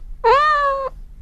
《音階が上がるマリンバ》フリー効果音
ゆっくり音階が上がっていくシンプルなマリンバ効果音。